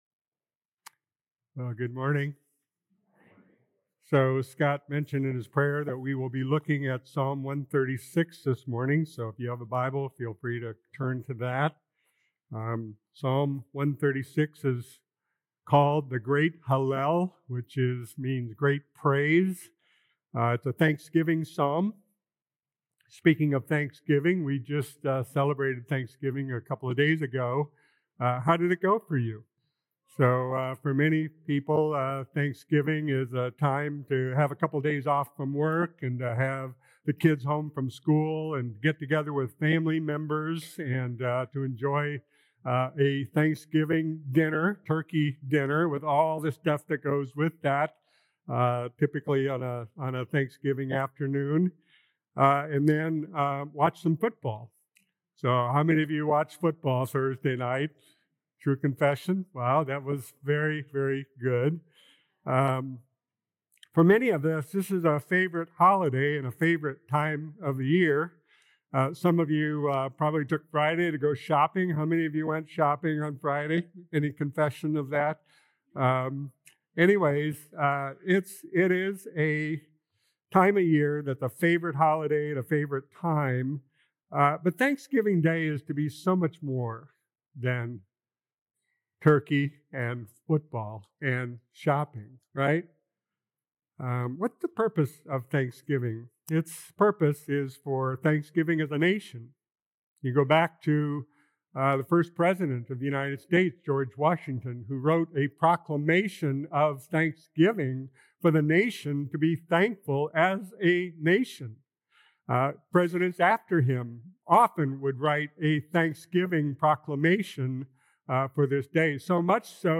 Nov 30th Sermon